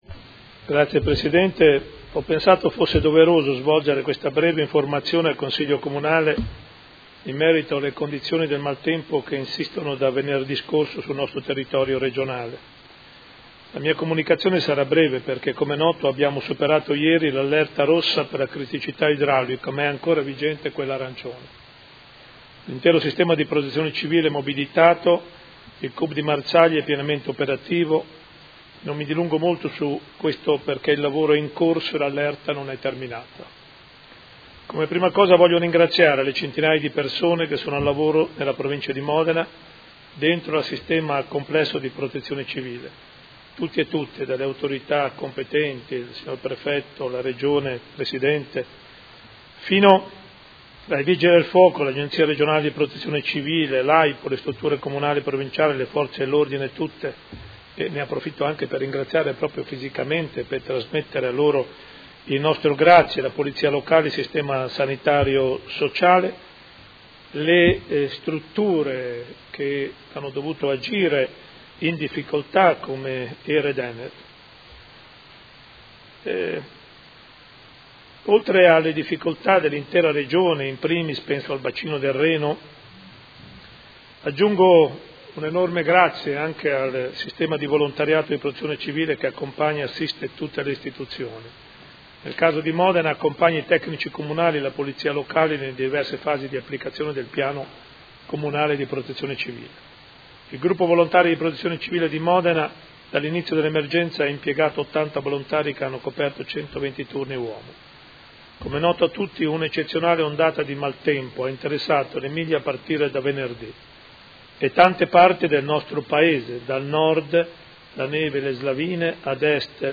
Seduta del 21/11/2019 Comunicazione sul maltempo.